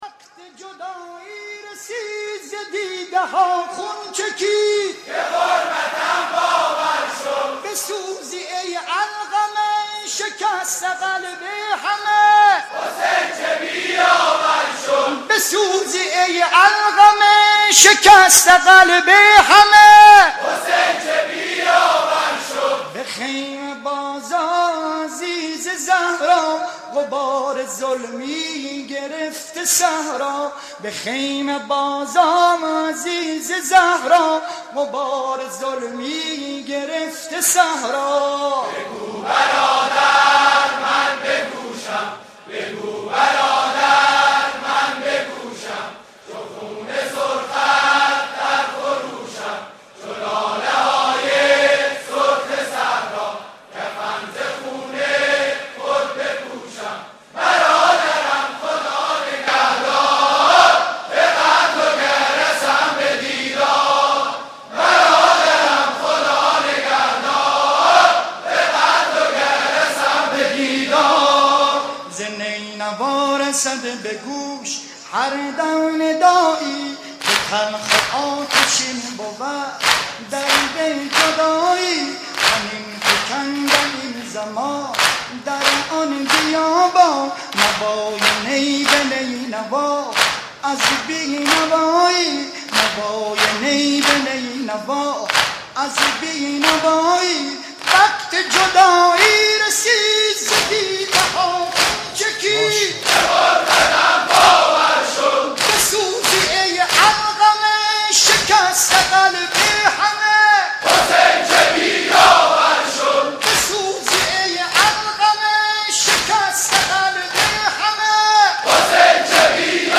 دانلود صوت: نوحه های هیئت فهادان یزد در سال نود وسه